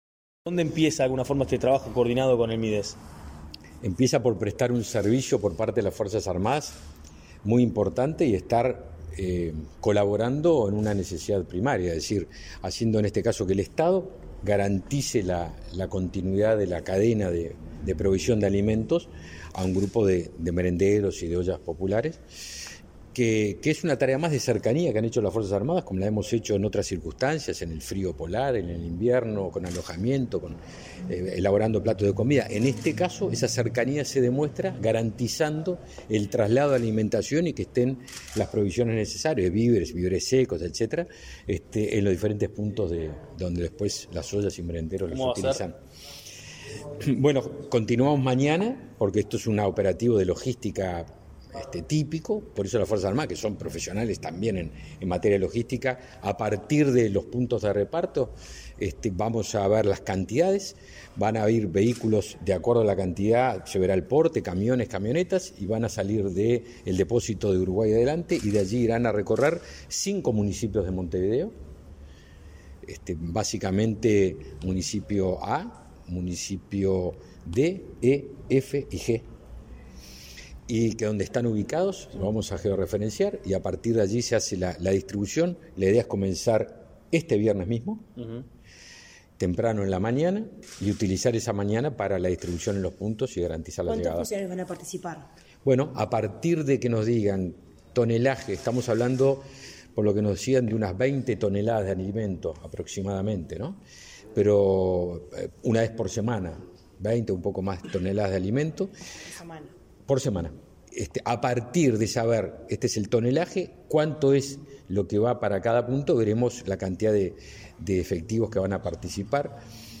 Declaraciones a la prensa del ministro de Defensa Nacional, Javier García
Declaraciones a la prensa del ministro de Defensa Nacional, Javier García 03/10/2022 Compartir Facebook X Copiar enlace WhatsApp LinkedIn El ministro de Defensa Nacional, Javier García, y el de Desarrollo Social, Martín Lema, mantuvieron una reunión, este 3 de octubre, respecto a acciones coordinadas de apoyo logístico de las Fuerzas Armadas al plan de alimentación del Mides. Tras el encuentro,García realizó declaraciones a la prensa.